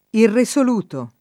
irreSol2to] agg. — anche irrisoluto [